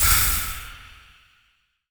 etfx_explosion_poisoncloud.wav